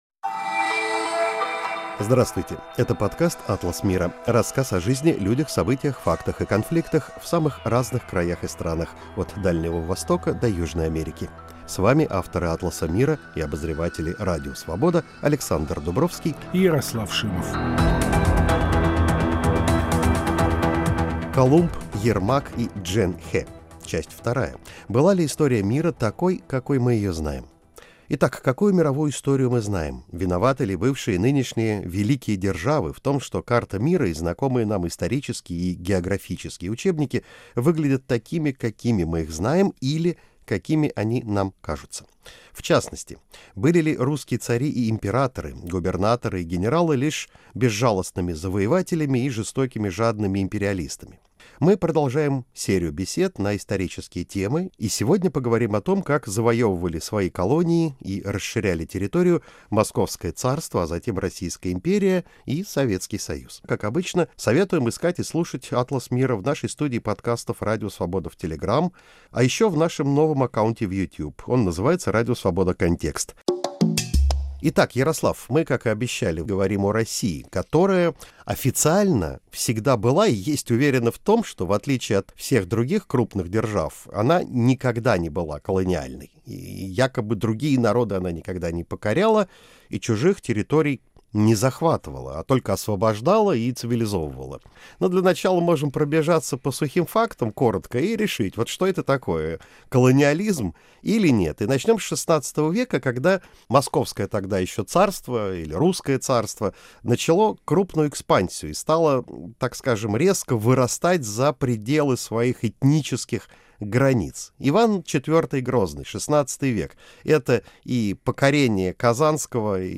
В новом выпуске – беседа о том, как завоевывали свои колонии и расширяли территорию Московское царство, а затем Российская империя и СССР.